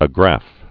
(ə-grăf)